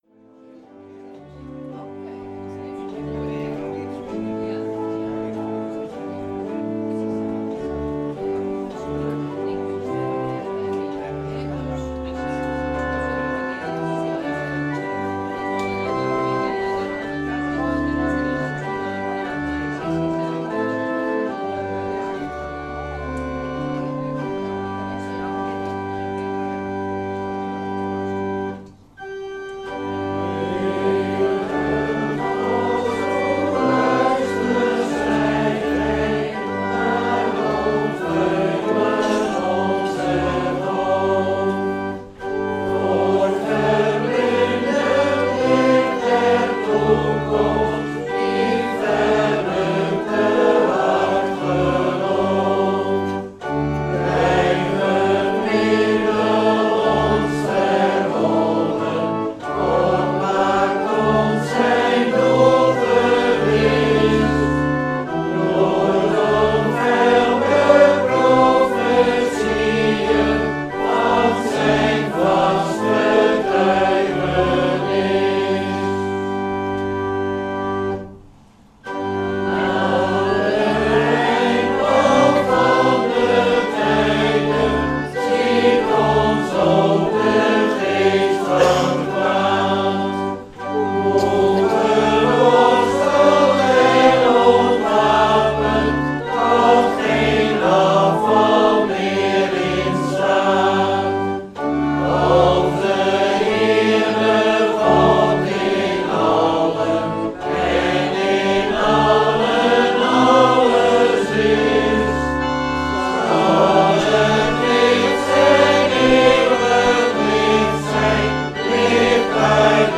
Schriftlezing: Mt 27 : 46 , Schriftlezing: Opb 20 : 10 - 15